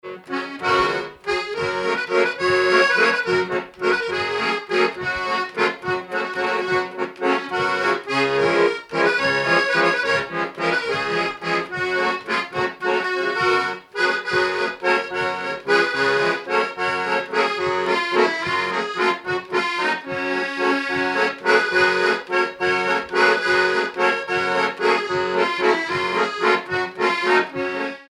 danse : valse
Pièce musicale inédite